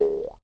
boing_5.ogg